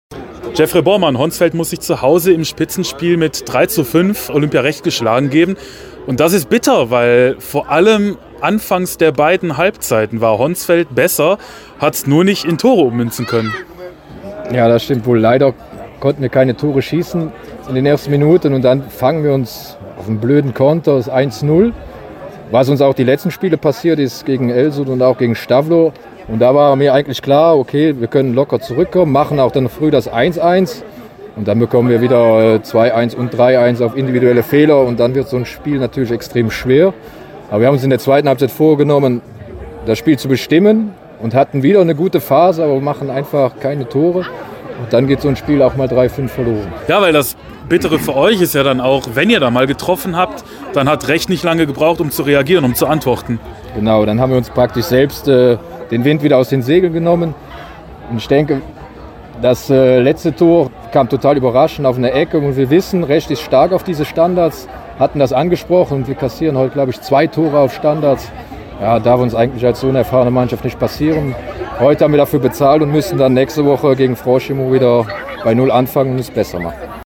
Mittelfeldspieler beim Honsfelder SV?